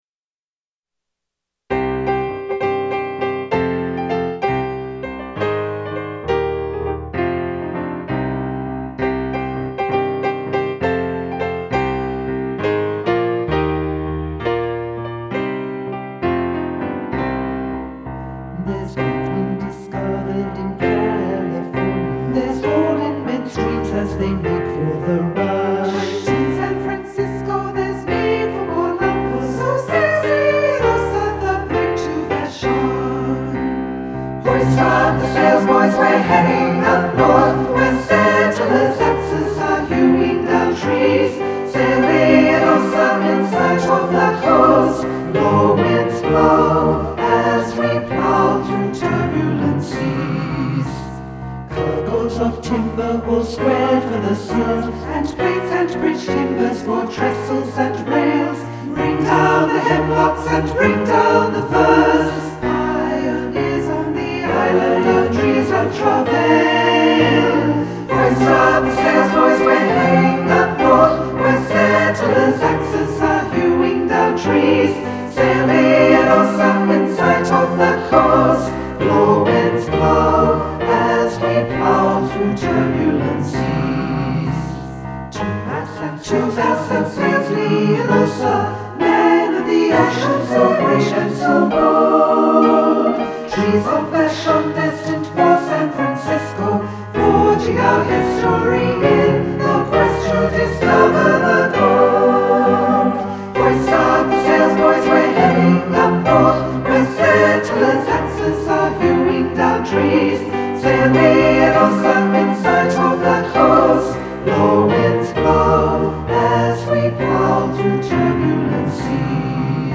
eleven songs for SATB choir
a rollicking sea shanty in varied compound time
For SATB and piano